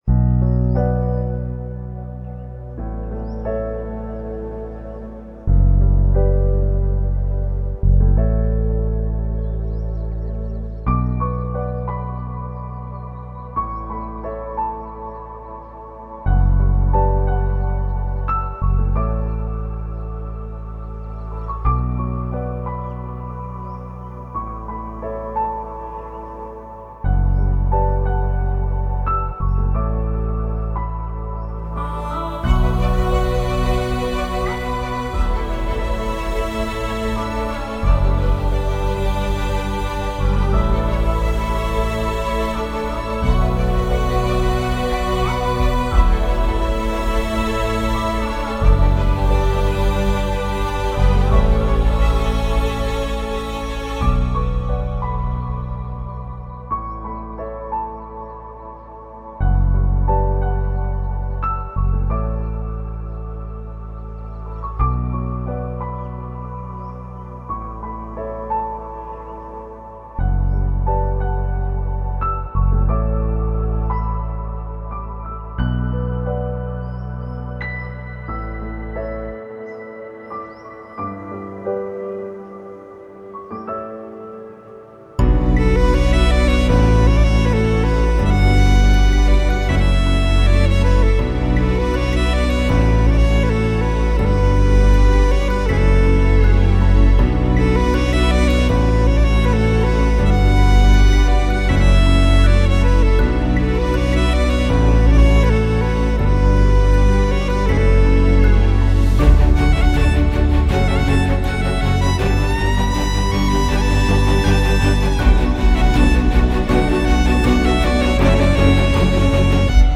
without dialogues